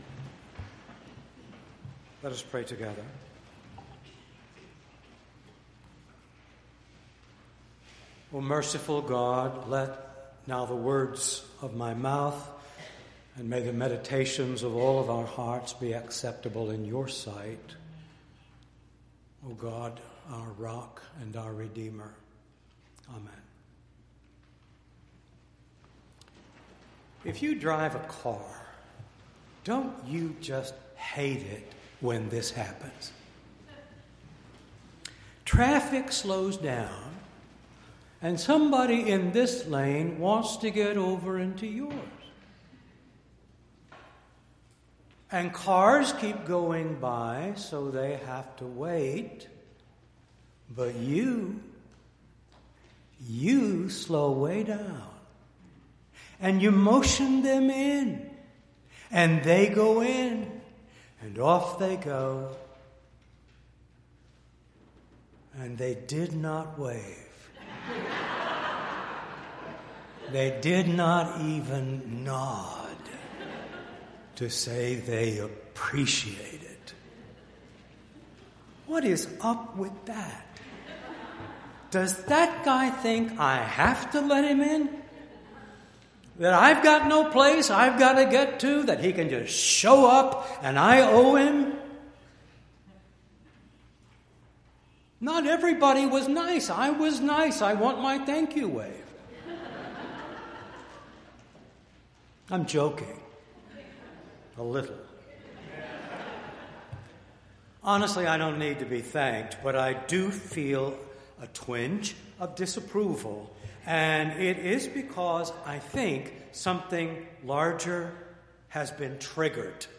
2-24-19-sermon.mp3